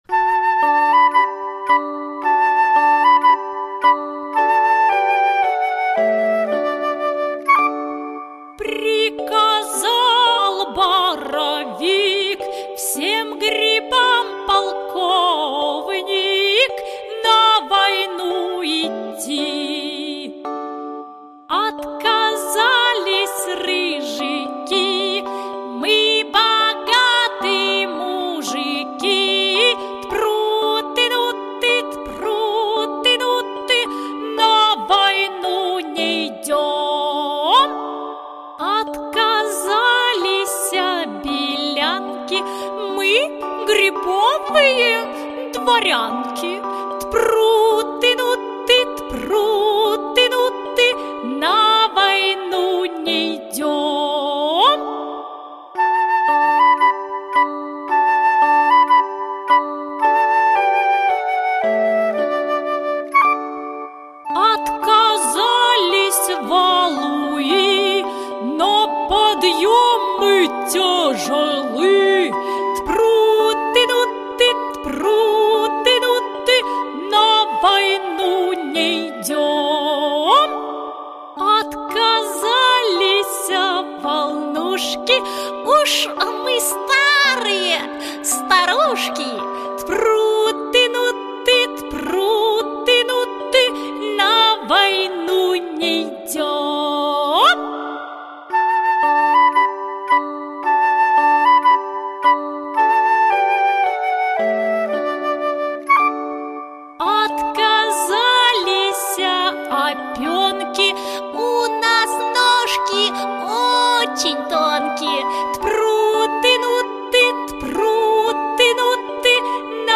Аудиокнига Нам 3 годика | Библиотека аудиокниг